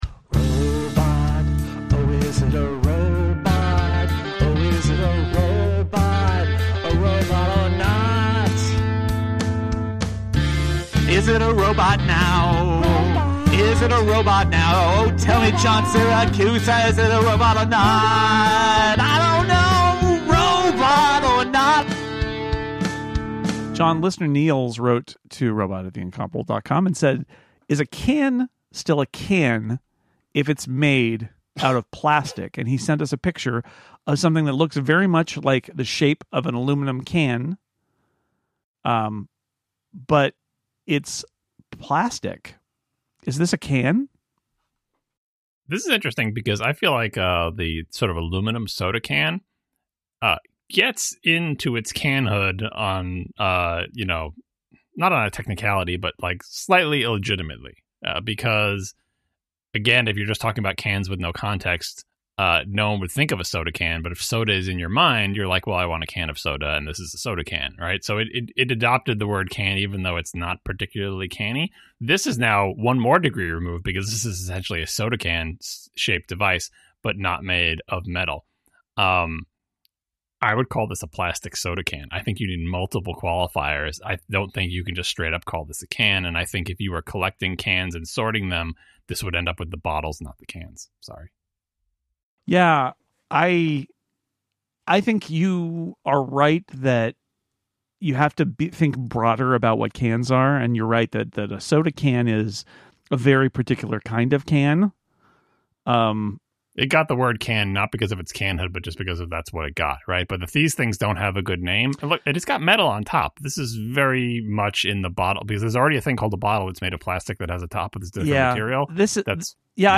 Hosts